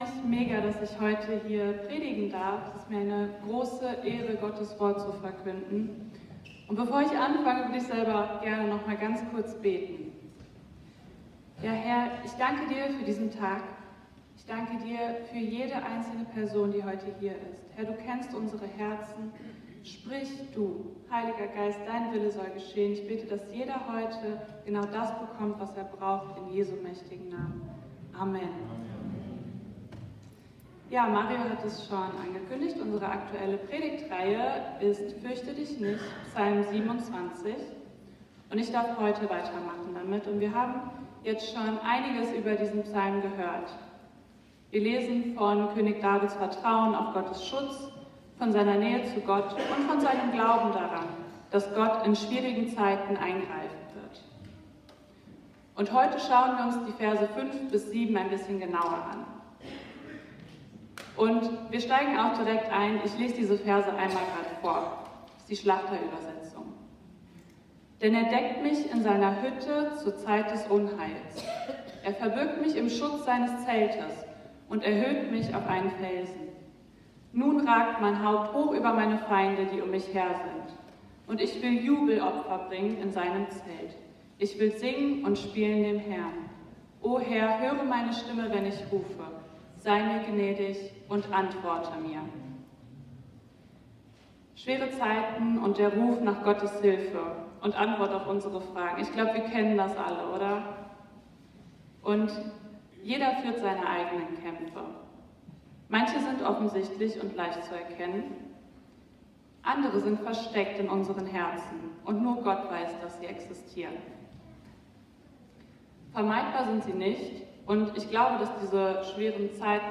Aktuelle Predigtreihe